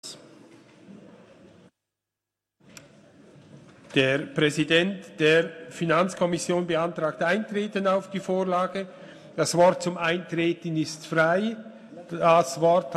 Session des Kantonsrates vom 17. bis 19. Februar 2020